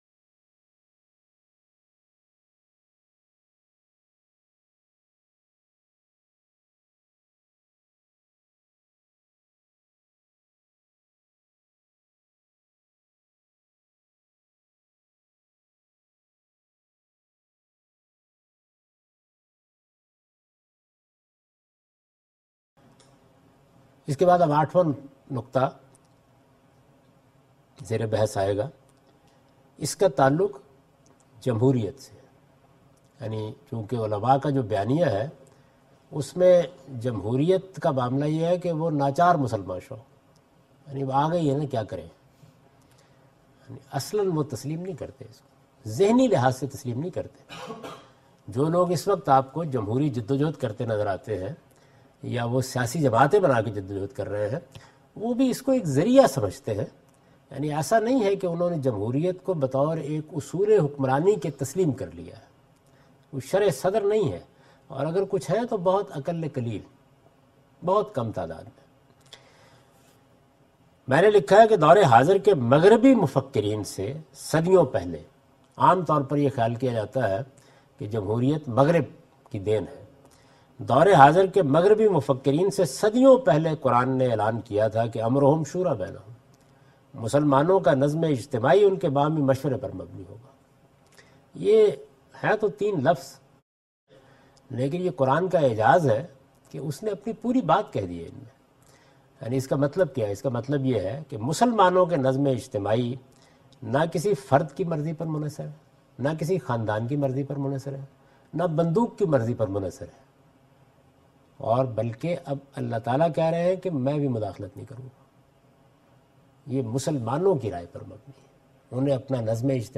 In this video, Javed Ahmad Ghamidi presents the "Counter Narrative" of Islam and discusses the "Democracy". This lecture was recorded on 19th January 2016 in Kuala Lumpur (Malaysia)